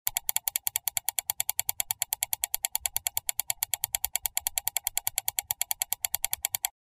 8-Многократное-нажатие-на-мышку
• Категория: Мышь компьютера
• Качество: Высокое